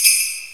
soft-hitclap2.wav